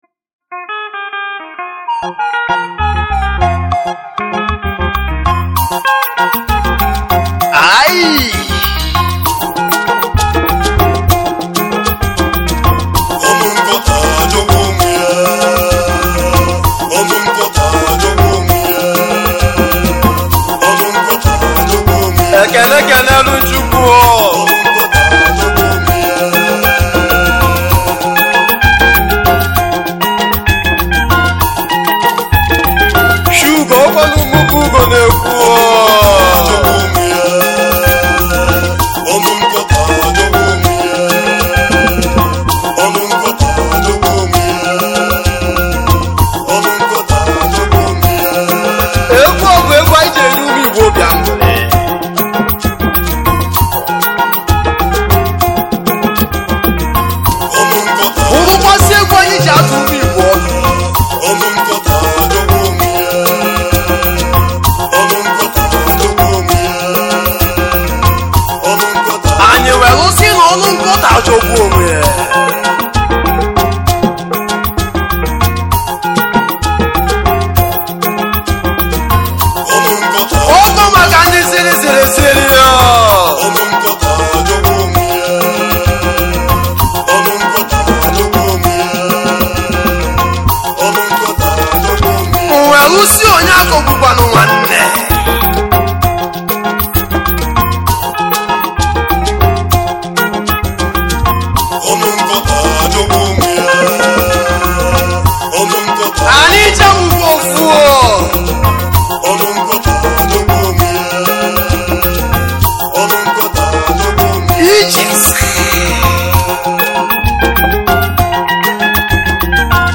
igbo highlife
highlife music band